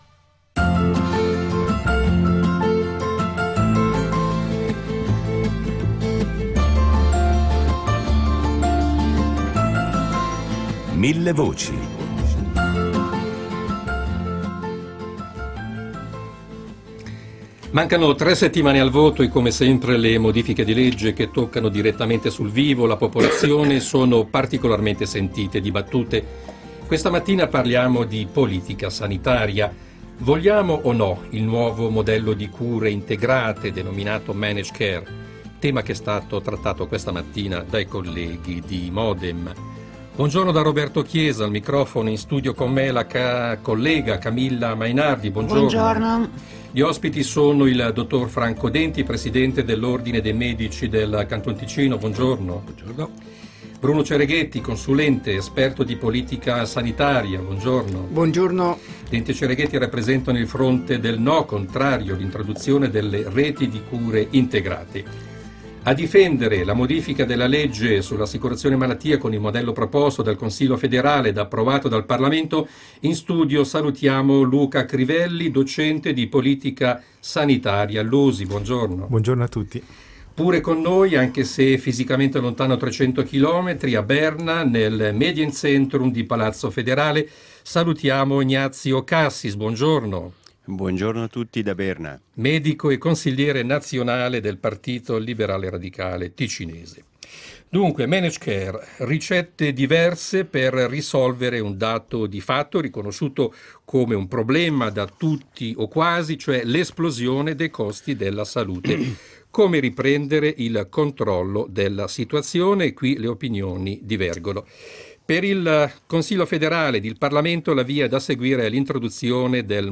Dibattito in vista della votazione popolare del 17 giugno
Riproponiamo il dibattito che si sta verificando nel paese e a Millevoci con 2 ospiti a favore e due contrari: tra cui due medici su fronti diversi.